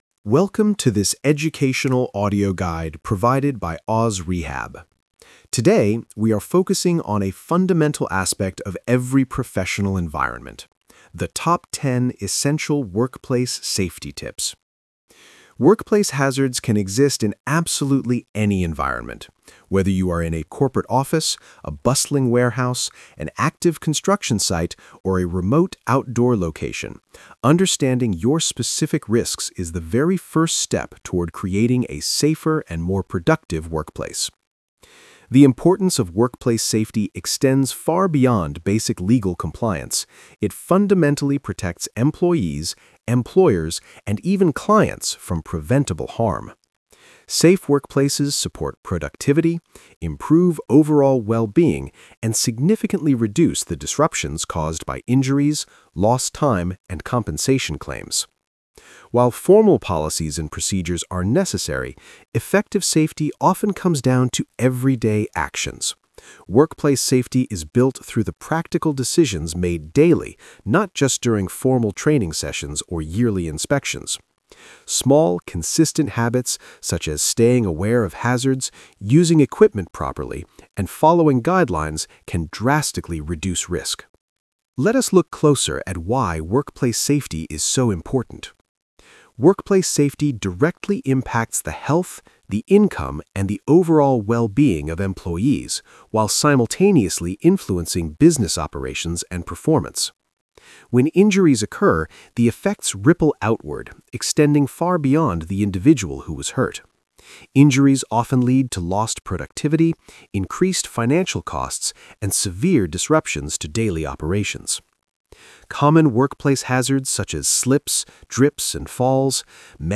Single-host narration